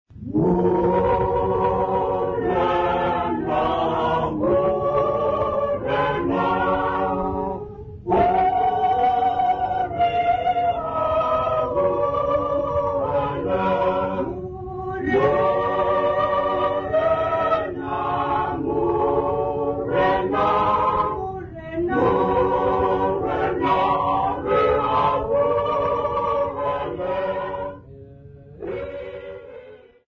Zwelitsha church congregation
Church music South Africa
Hymns, Xhosa South Africa
Folk music South Africa
field recordings
Unaccompanied church song produced at Zwelitsha church music composition workshop (Kyrie).